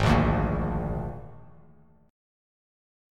Absus2b5 chord